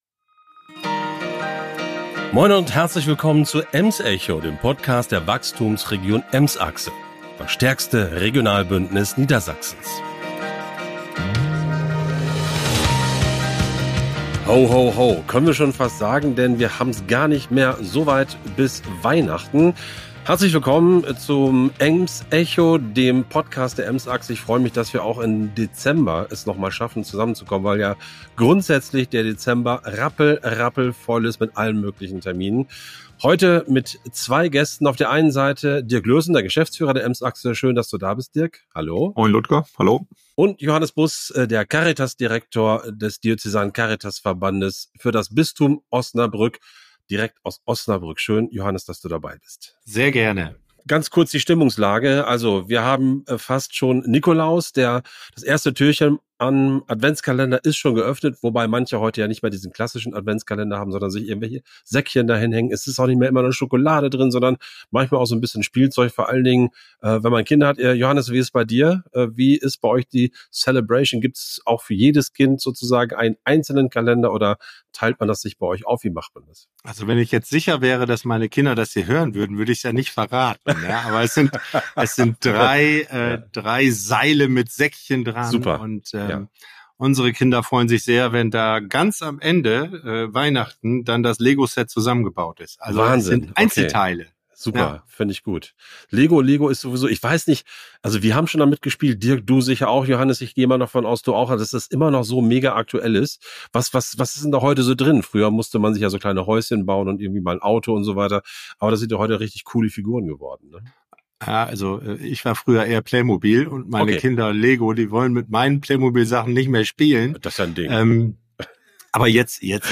Die drei diskutieren die Bedeutung von Gemeinschaft und stellen die Unterstützungsmöglichkeiten durch die Caritas und ihre sozialen Einrichtungen vor. Weitere Themen sind die Fachkräftegewinnung, Verdienstmöglichkeiten im sozialen Sektor, die Rolle von Stiftungen und Ehrenämtern sowie die soziale Verantwortung von Unternehmen in der Region.